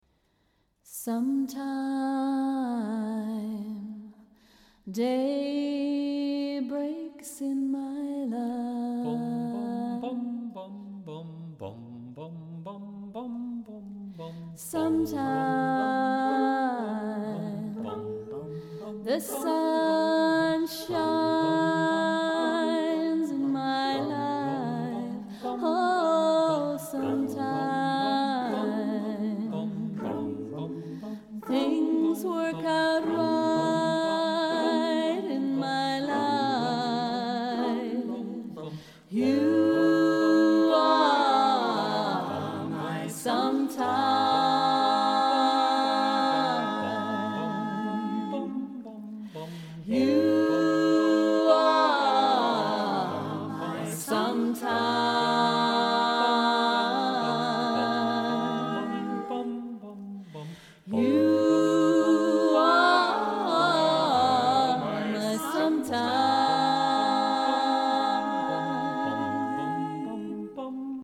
calm and riotous, exultant and tranquil